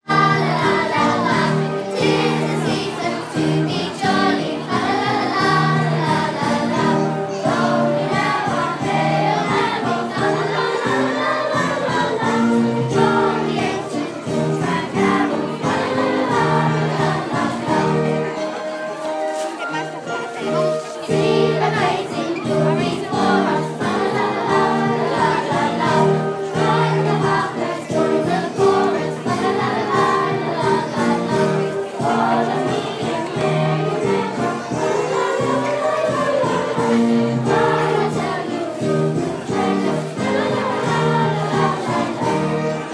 KS2 choir perform Deck the Halls